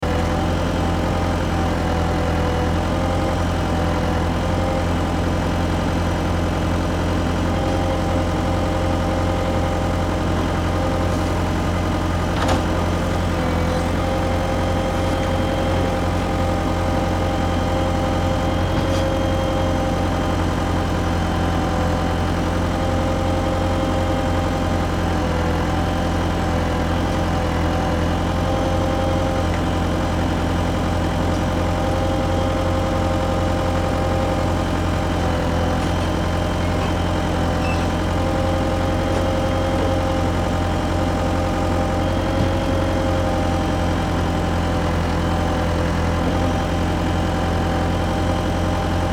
Звуки экскаватора
Звук движения экскаватора, запись из кабины